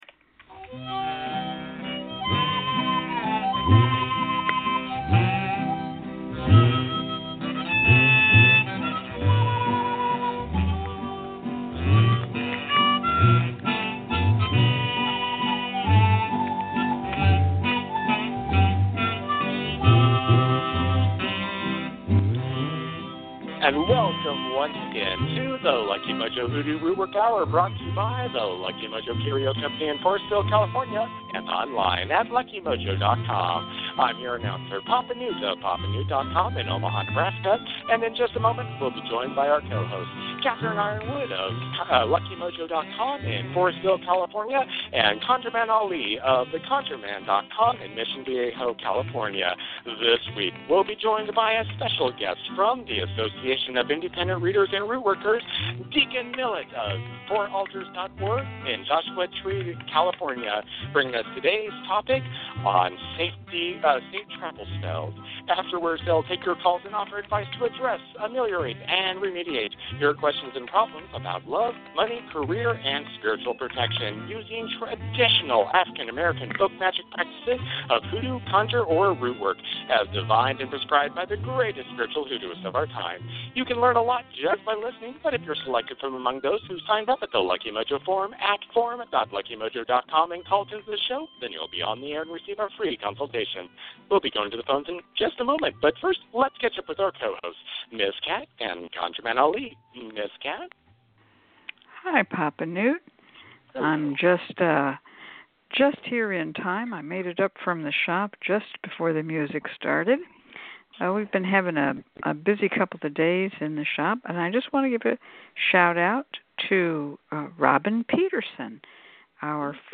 We'll provide free readings, free spells, and consultations to callers, and give listeners an education in African-American folk magic. We begin this show with a Discussion Panel focussed on the topic of Safe Travel Spells.